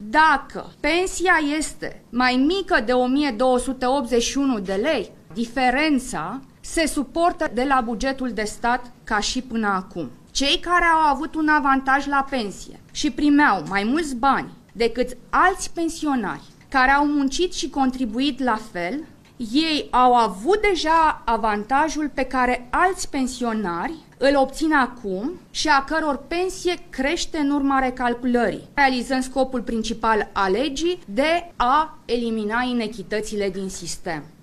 Simona Bucura- Oprescu a precizat, la o conferinţă de presă, că aproximativ 700 de mii de pensionari nu vor primi mai mulţi bani în urma acestei reforme şi a explicat că unii dintre ei au avut perioade de contributivitate foarte scurte, iar ceilalţi au obţinut beneficii mai mari potrivit legilor în vigoare la momentul pensionării, faţă de contribuţiile plătite.